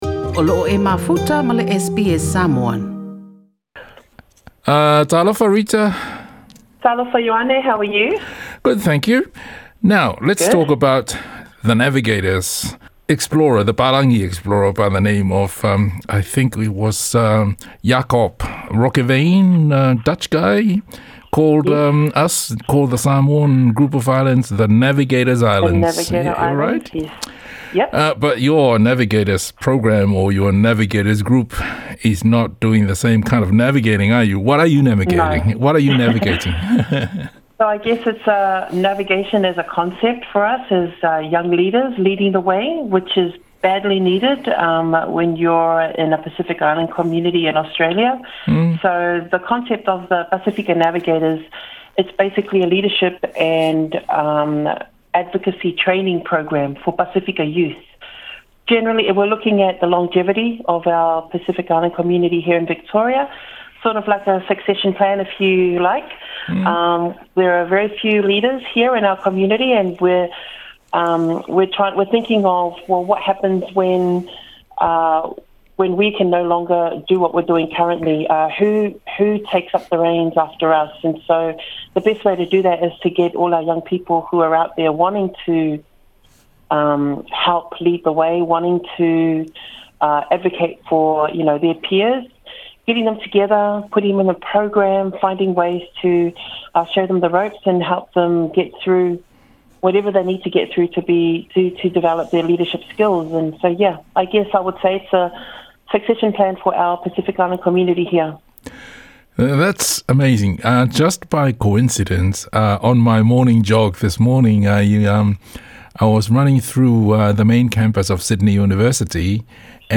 talanoaga